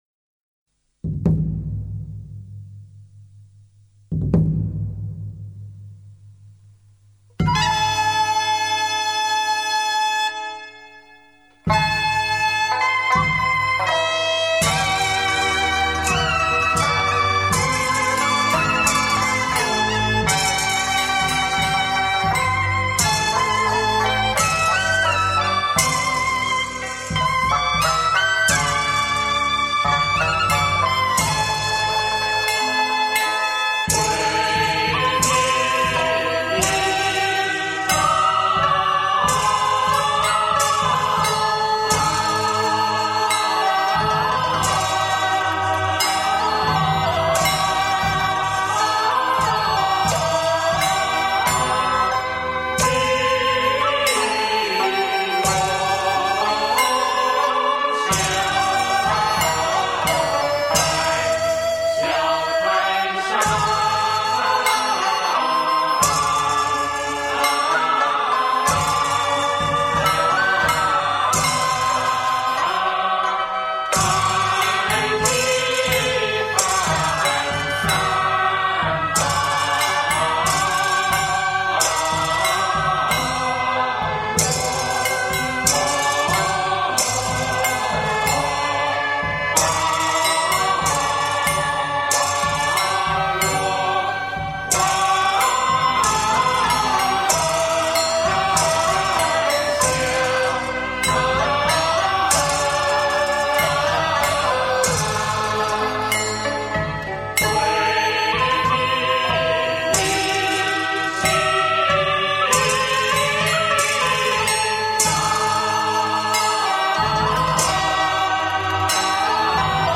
选取道教仪式中的部分曲目，请上海名家演唱，充分表现出道家韵腔的况味。